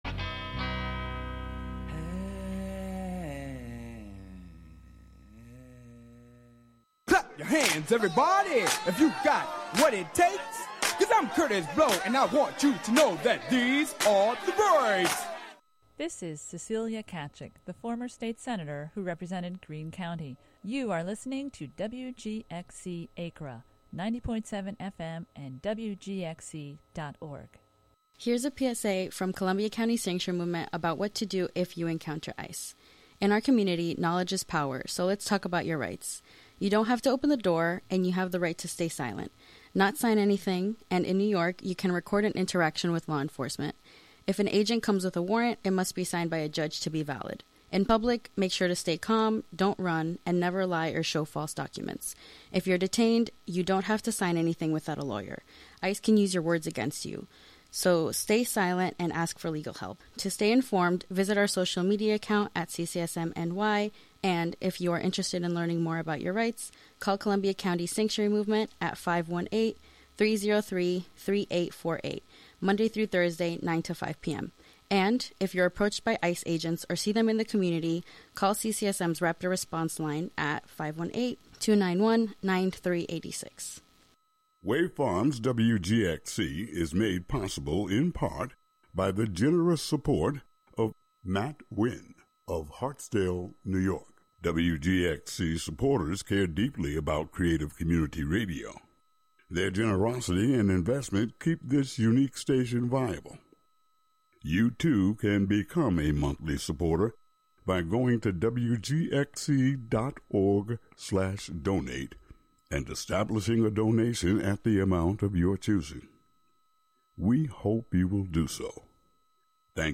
Live from the Catskill Maker Syndicate space on W. Bridge St. in Catskill, "Thingularity" is a monthly show about science, technology, fixing, making, hacking, and breaking with the amorphous collection of brains comprising the "Skill Syndicate."